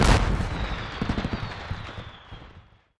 firework_explosion_03.ogg